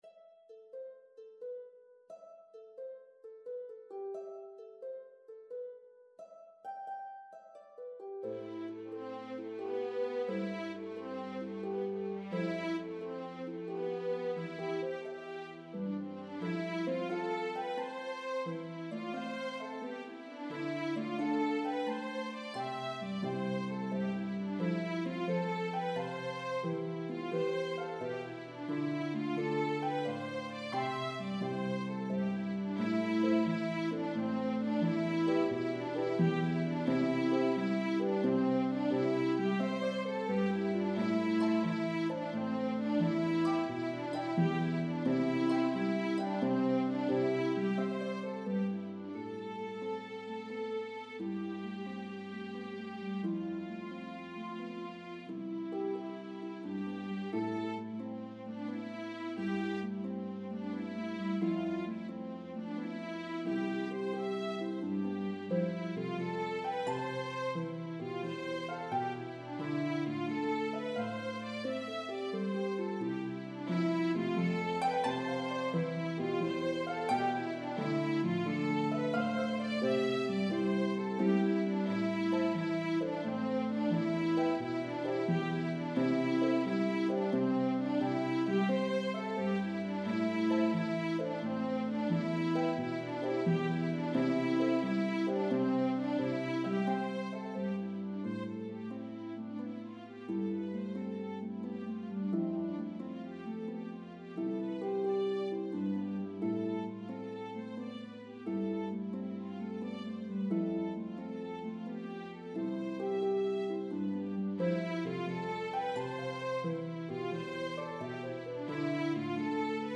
This entertaining 5+ minute medley begins sweetly
slip jig in 9/8
reel in 4/4
jig in 6/8
The melodies are shared between instruments.